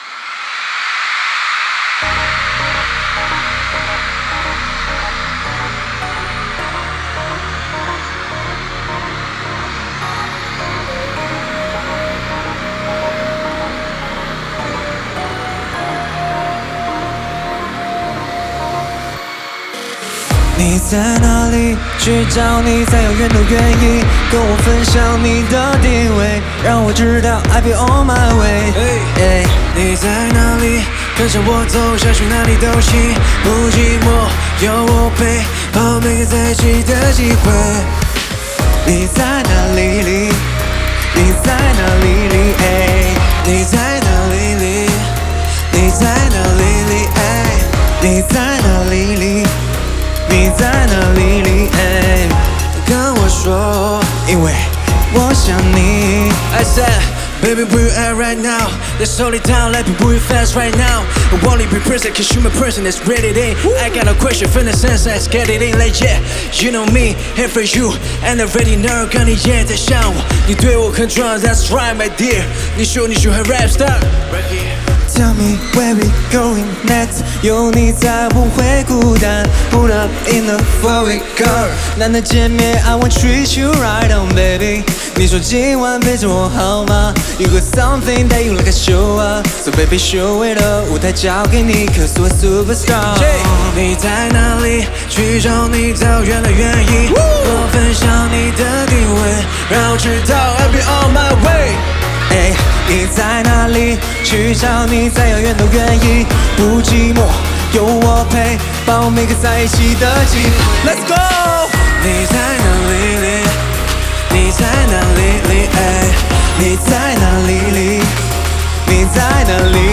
共享舞台盛宴